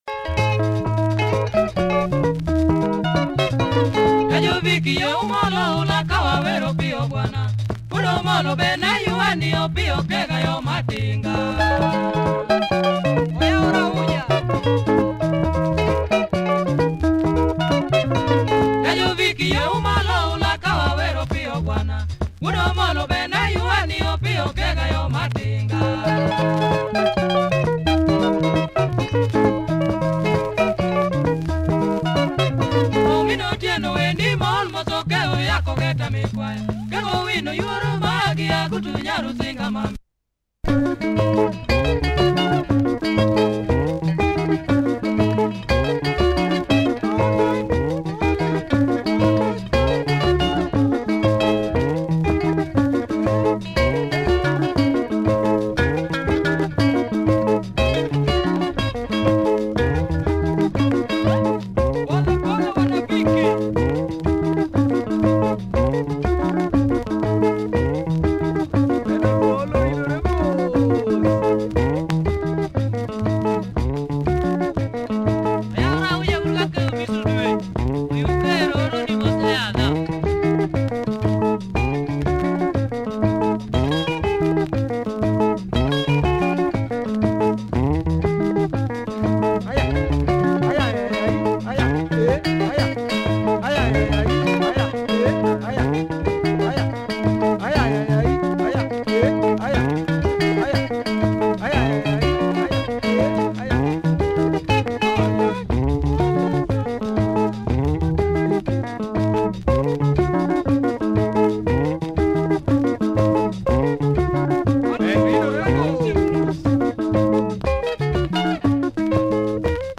Great LUO benga, solid guitar groove, check the uptempo